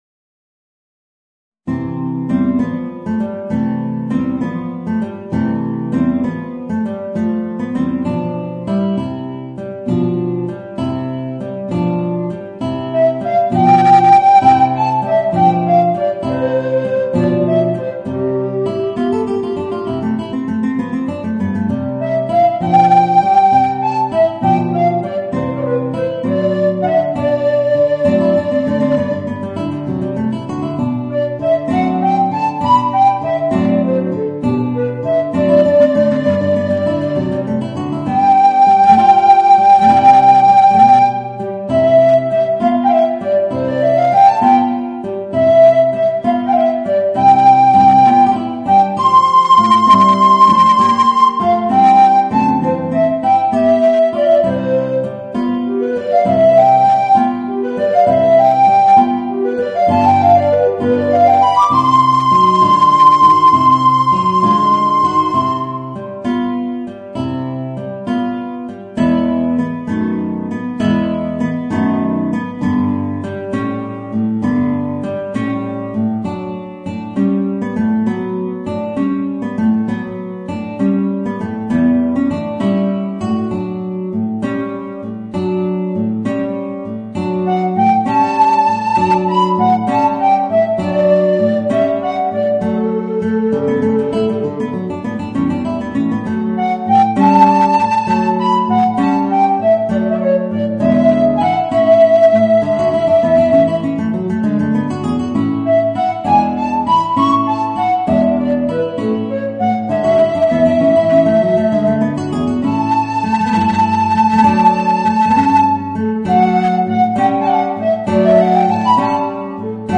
Voicing: Guitar and Alto Recorder